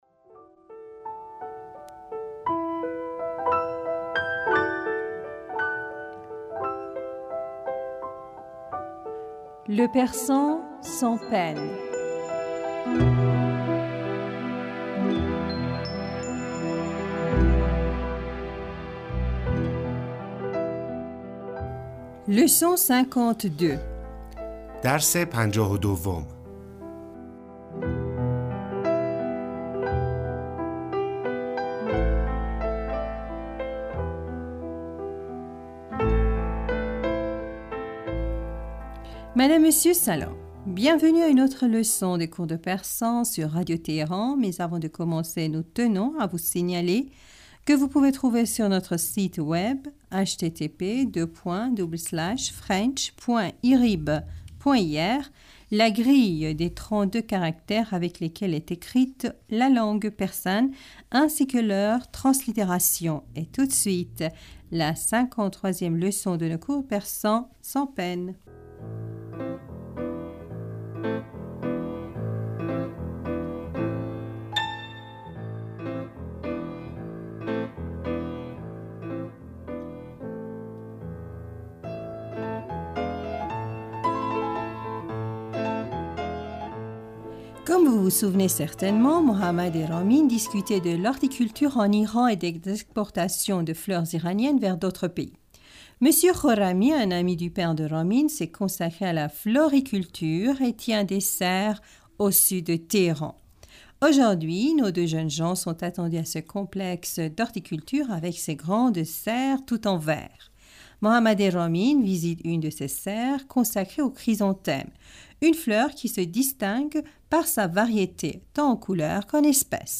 Bienvenus à une autre leçon des cours de persan.